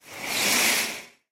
Звук перехода к следующему слайду